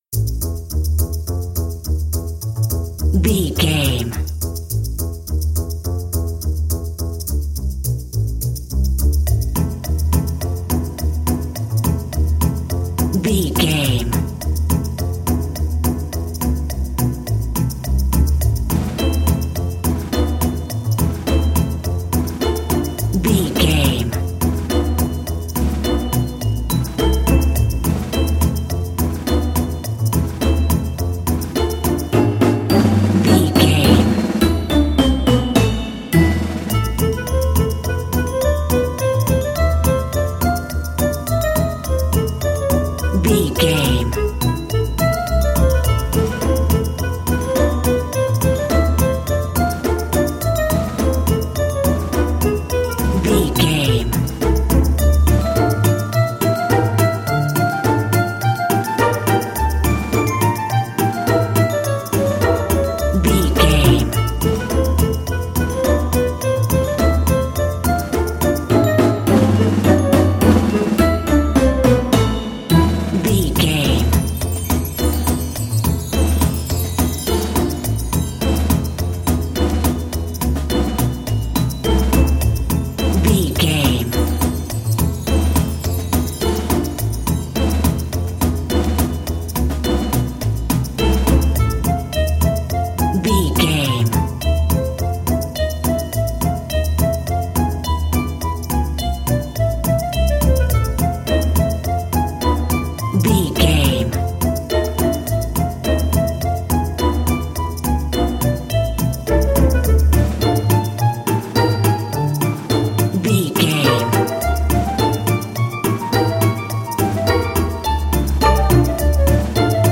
Aeolian/Minor
percussion
strings
double bass
synthesiser
circus
goofy
comical
cheerful
perky
Light hearted
secretive
quirky